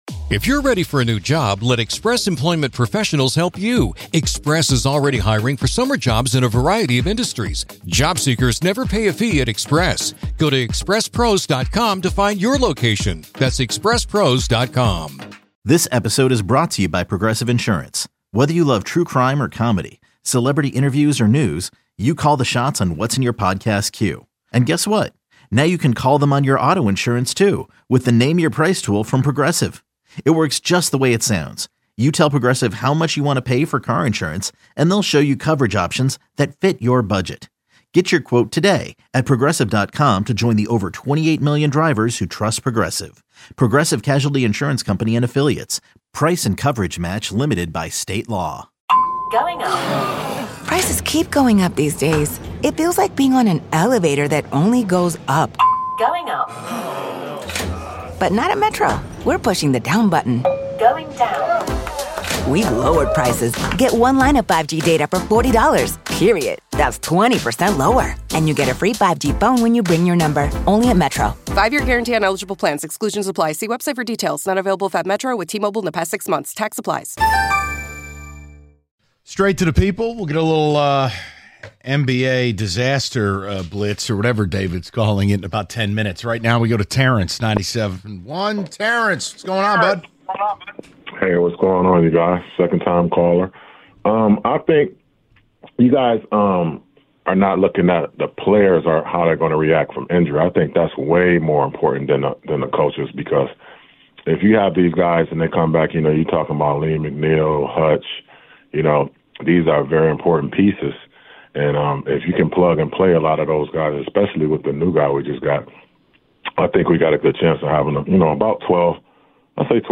Taking More Of Your Lions' Win Total Calls